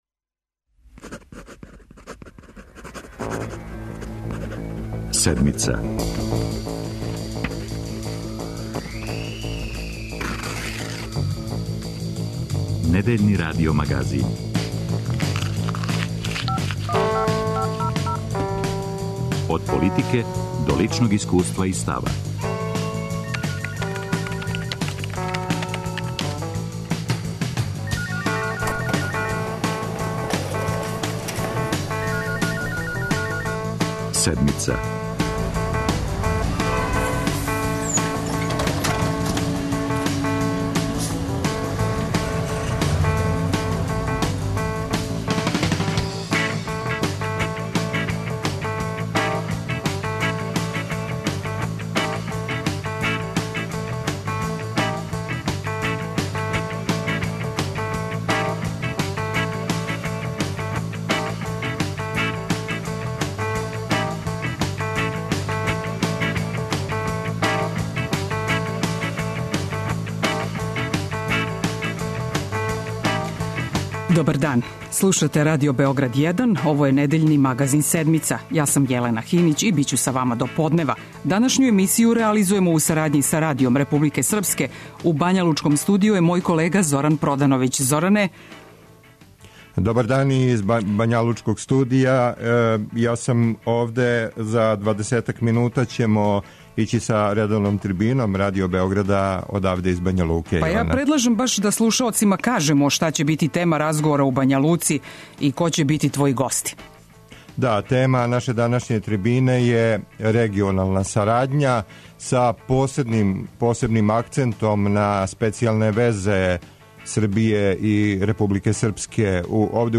Правимо радио мост Београд- Бања Лука. Тема: односи и специјалне везе Србије и Републике Српске.